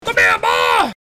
Come Here Boy Meme Sound Effect Download | Gfx Sounds
Come-here-boy-meme.mp3